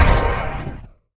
shadowimpact2.wav